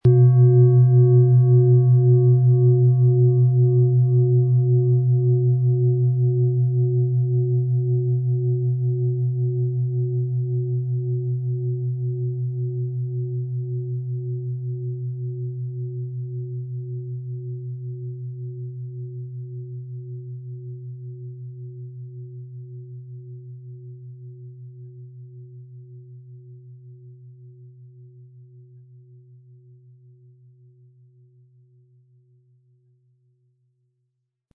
Planetenton
Es ist eine von Hand getriebene Klangschale, aus einer traditionellen Manufaktur.
Um den Original-Klang genau dieser Schale zu hören, lassen Sie bitte den hinterlegten Sound abspielen.
Durch die traditionsreiche Fertigung hat die Schale vielmehr diesen kraftvollen Ton und das tiefe, innere Berühren der traditionellen Handarbeit
Mit dem beiliegenden Klöppel wird Ihre Klangschale mit schönen Tönen klingen.
MaterialBronze